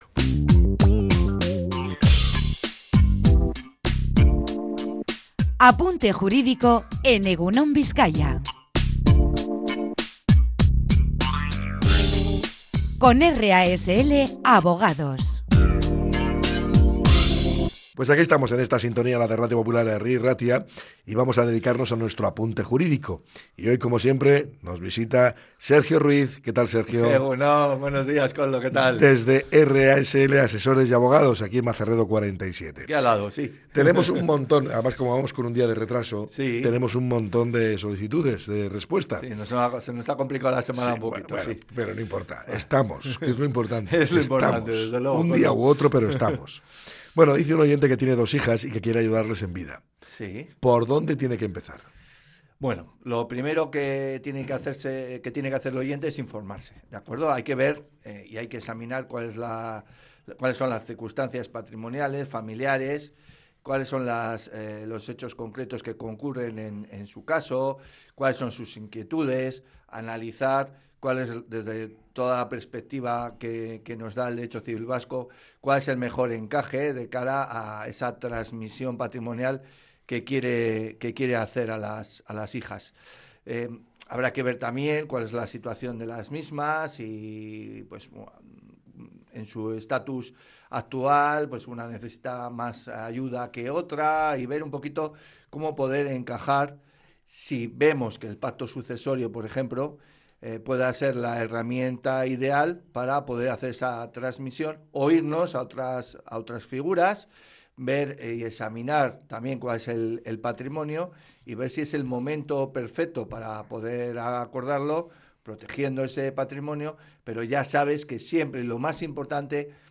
RASL Abogados responde a las dudas de los oyentes